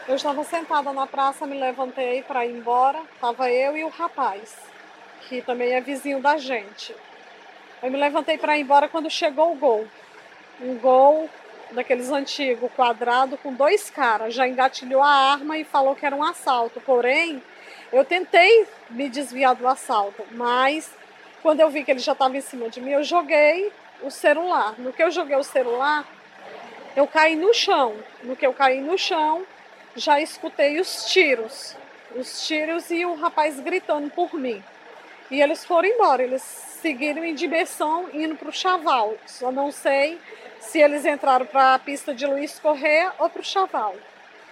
Confira o áudio de uma das vítimas: